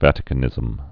(vătĭ-kə-nĭzəm)